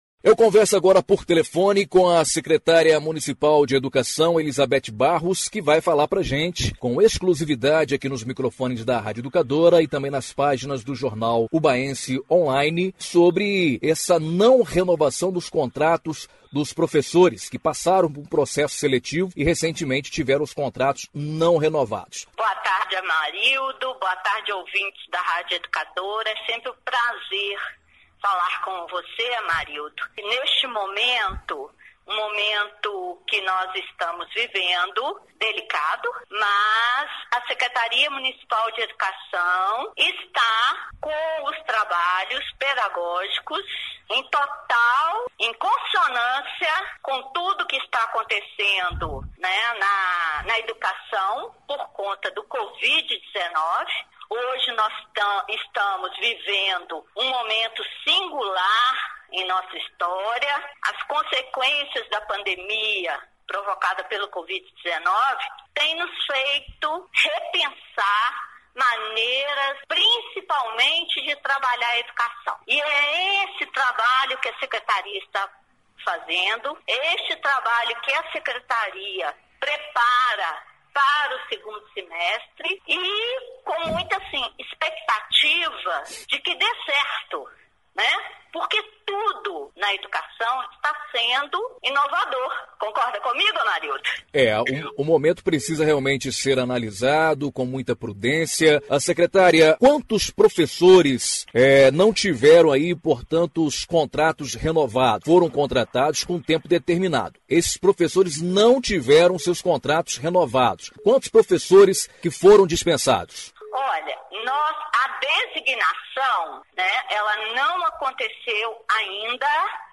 ENTREVISTA-SECRETARIA-BETH-26´09.mp3